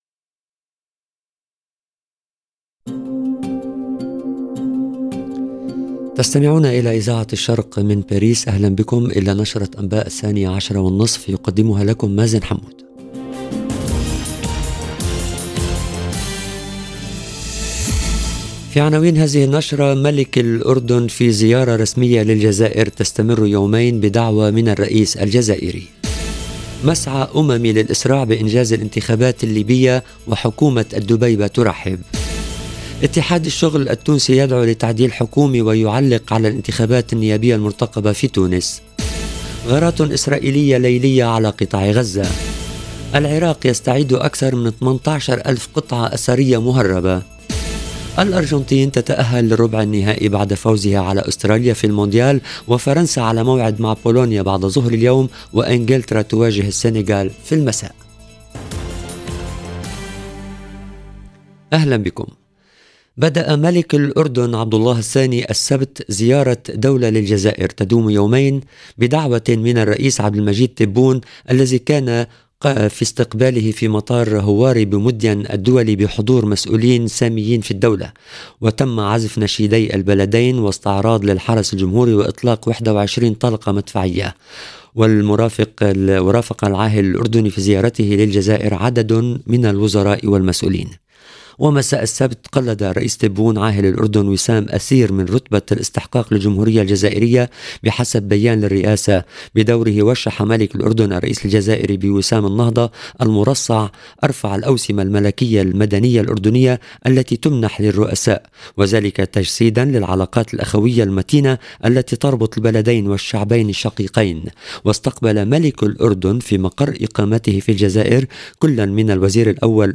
LE JOURNAL EN LANGUE ARABE DE MIDI 30 DU 4/12/22